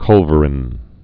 (kŭlvər-ĭn)